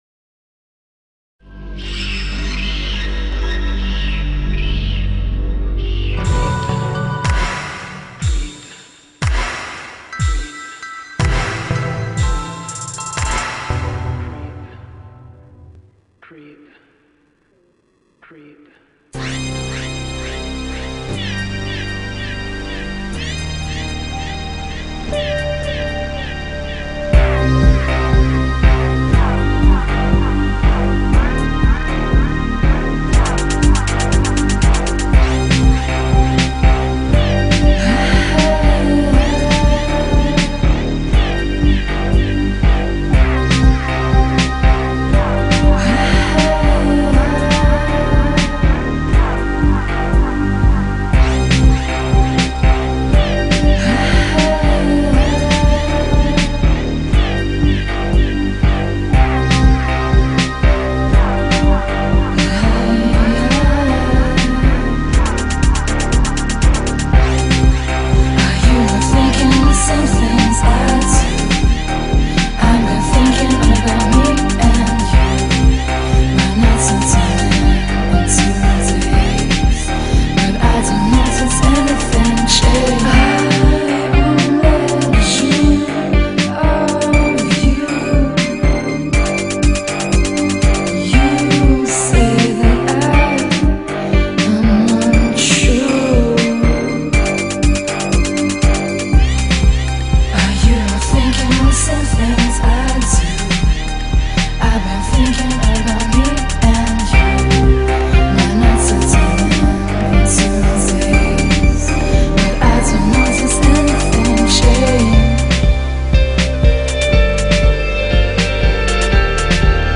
Brooklyn duo
ethereal voice